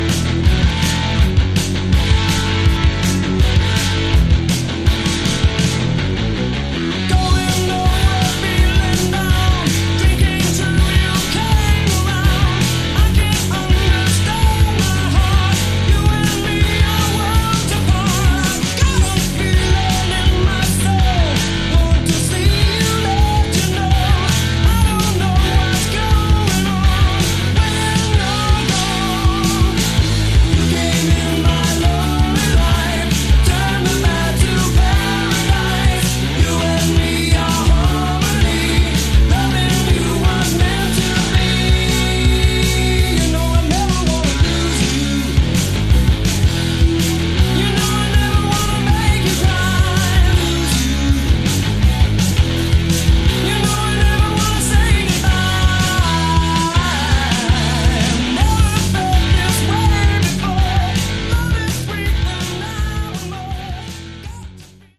Category: Hard Rock
vocals
guitar
bass
Keys
drums